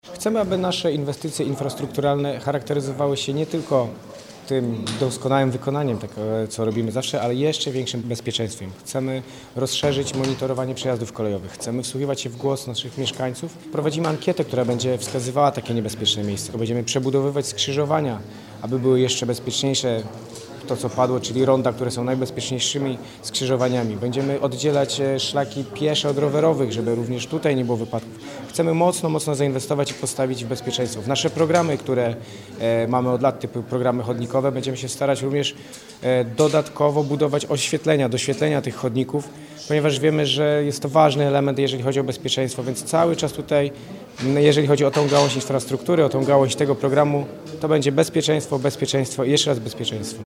– Celem programu jest zwiększenie bezpieczeństwa wszystkich użytkowników dróg – kierowców, pieszych i rowerzystów – a także pasażerów kolei – mówi Michał Rado, wicemarszałek WojewództwaDolnośląskiego.